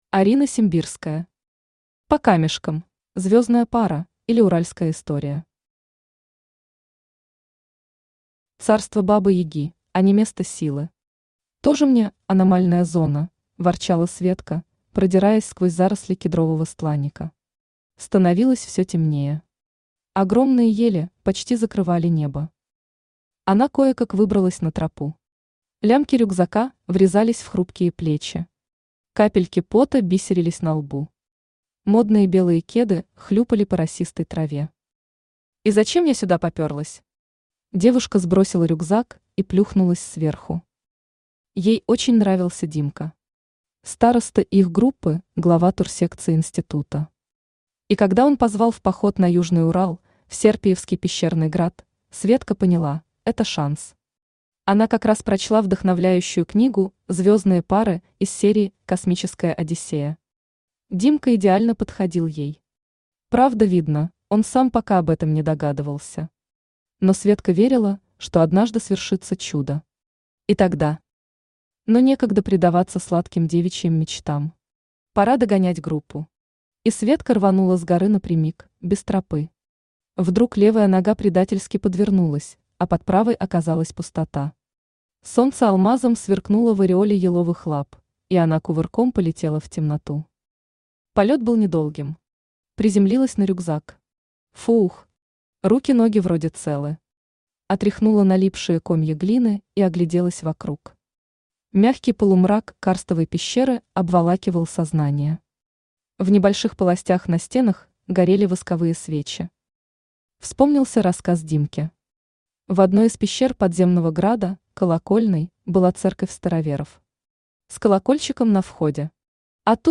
Aудиокнига По камешкам Автор Арина Симбирская Читает аудиокнигу Авточтец ЛитРес.